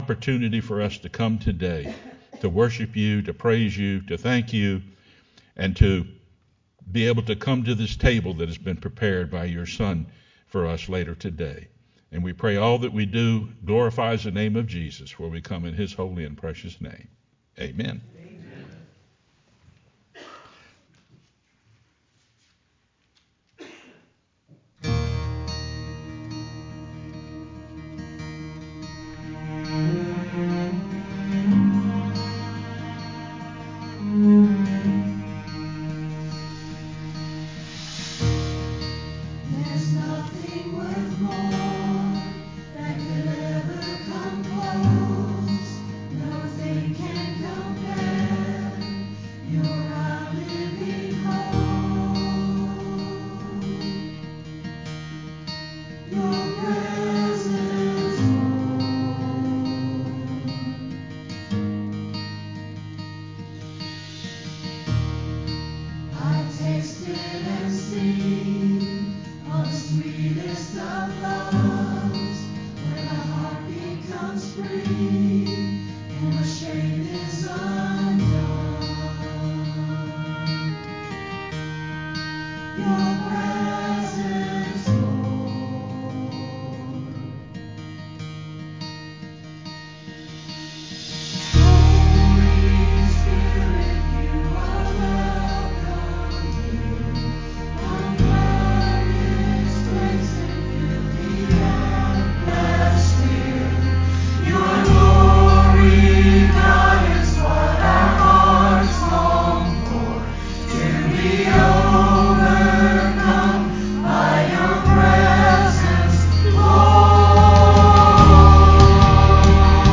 sermonMar02-CD.mp3